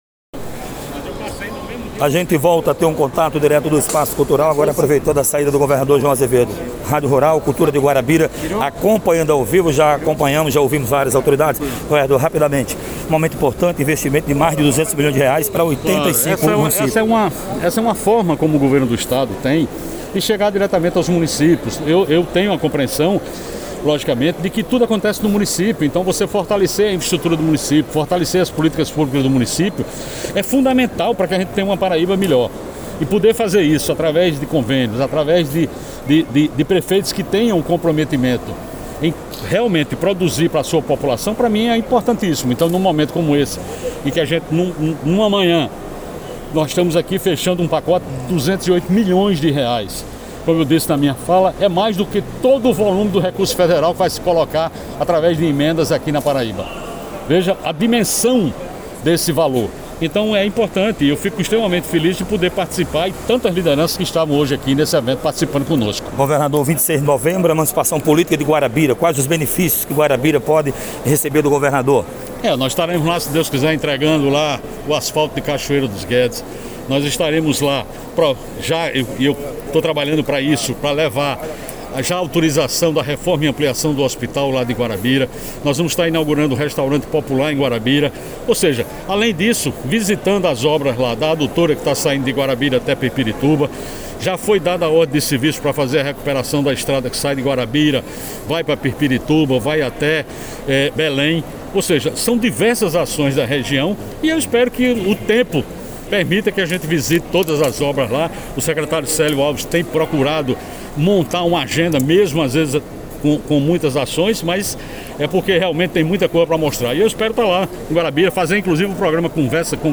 conversou com o Governador que inclusive afirmou o compromisso que também tem com Guarabira e destacou alguns pontos de mudanças e melhorias inerentes a cidade que precisam serem feitas.
002-governador.mp3